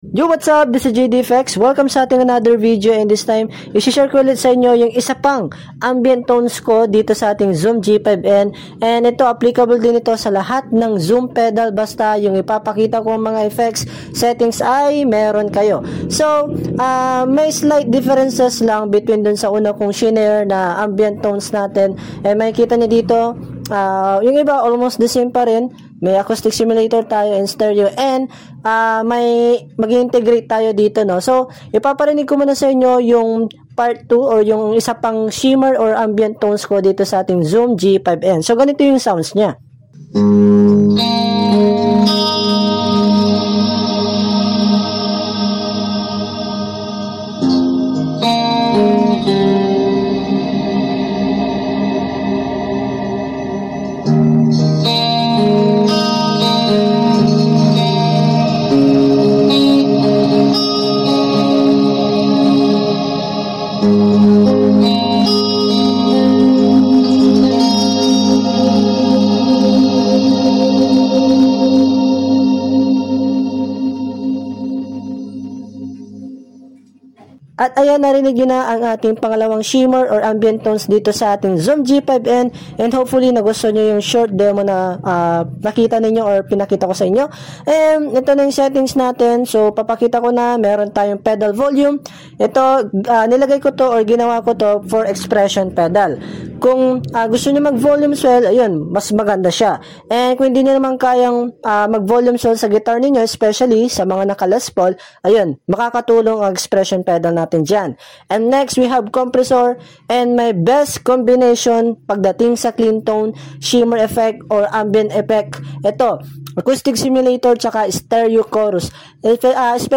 ZOOM G5n - Ambient/Shimmer FREE